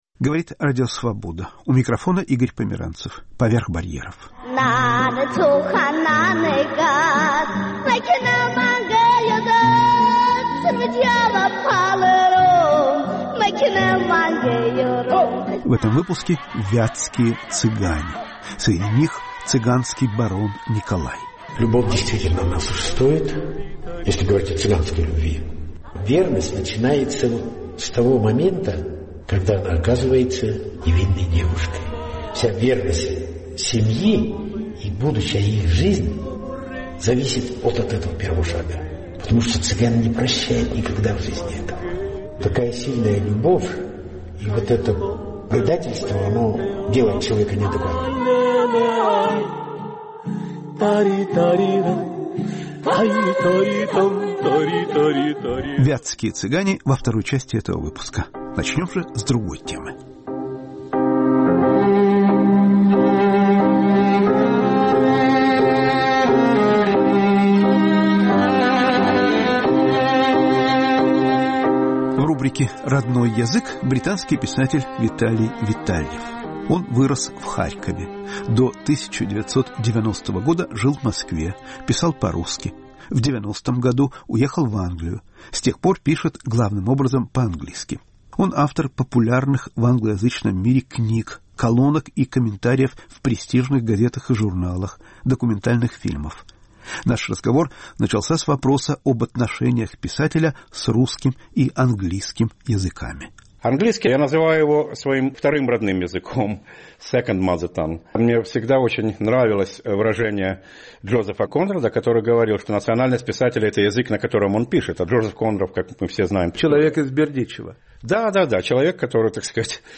О двух родных языках. Беседа